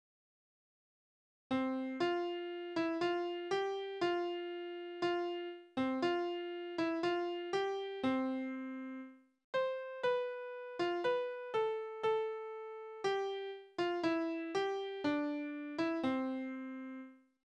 Tonart: C-Dur
Taktart: 4/4
Tonumfang: Oktave
Besetzung: vokal